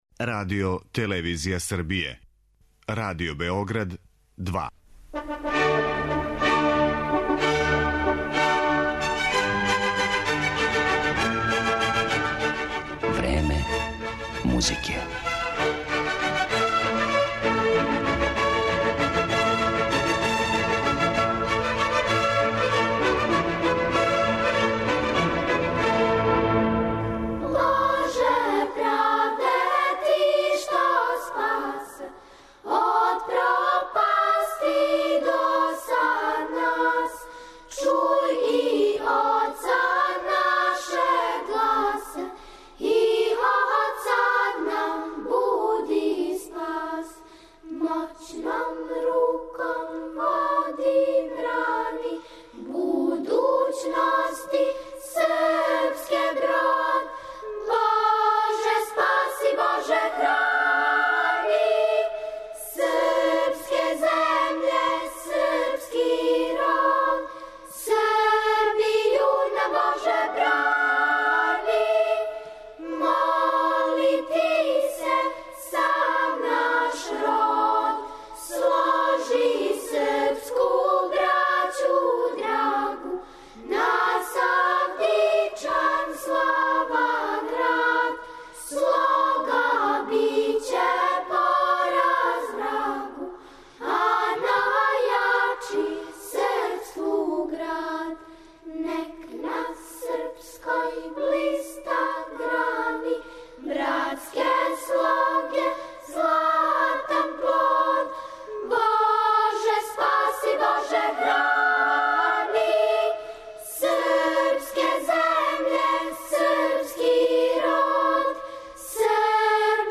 Репортажа са овог догађаја испуниће данашње Време музике.